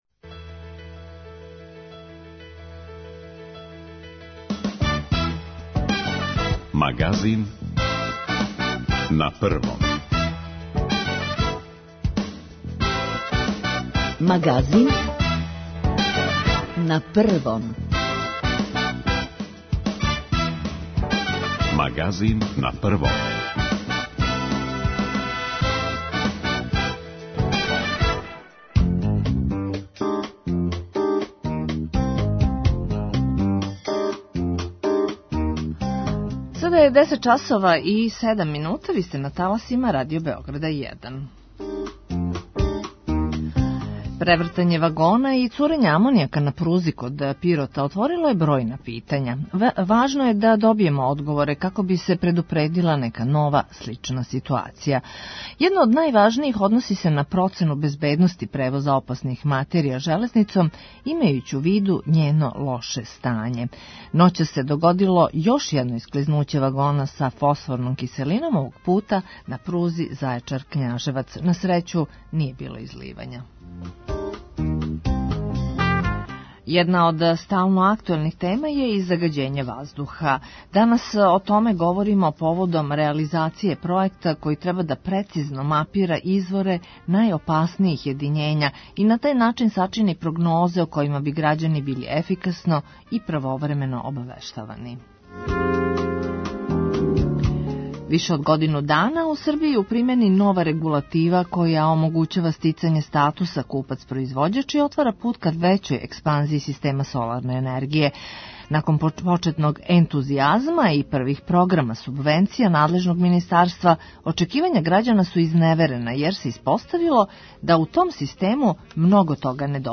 Да ли су наше пруге довољно безбедне за превоз опасног терета и које мере предострожности у транспорту, генерално, морају бити предузете? о томе ћемо разговарати са Гораном Триваном из Института за мултидисциплинарна истраживања, иначе бившим министром екологије.